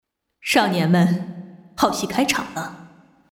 女声
英雄联盟角色模仿-44婕拉